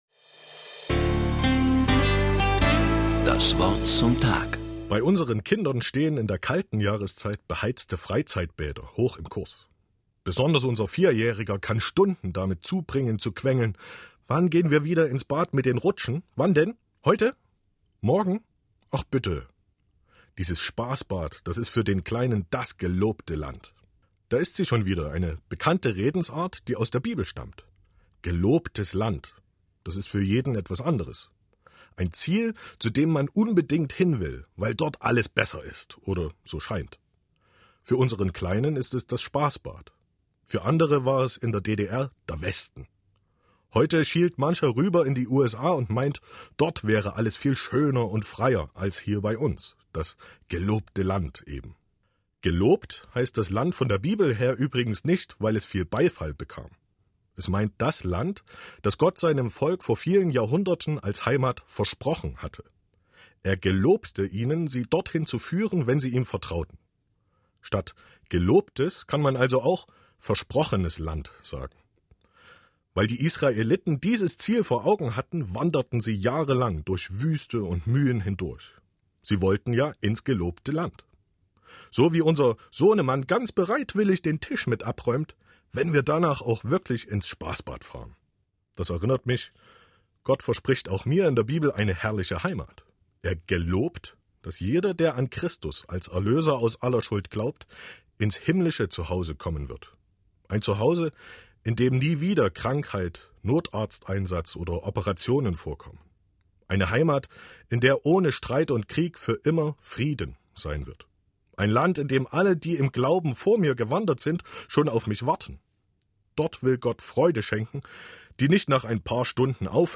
In der Woche vor dem Ewigkeitssonntag hatte unsere Kirche in Sachsen die Möglichkeit, das „Wort zum Tag“ im MDR zu gestalten.